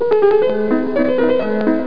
klavier.mp3